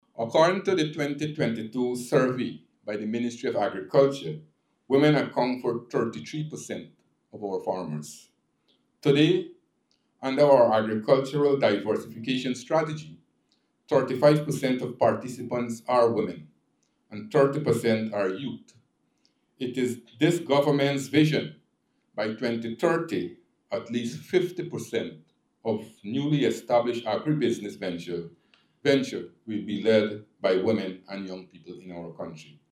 Minister of Agriculture Zulfikar Mustapha commended the initiative, which he says is a beacon of opportunity for innovation and empowerment. Speaking at the initiative, Minister Mustapha says it coincides with the government’s plan to get more women involved in agriculture and agro businesses.